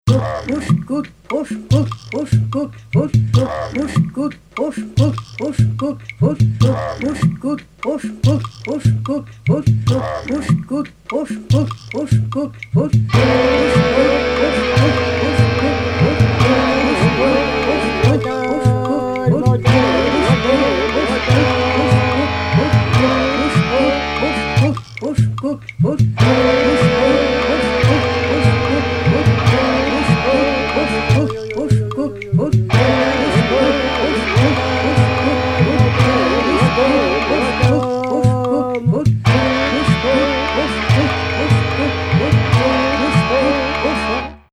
basse
batterie, percussions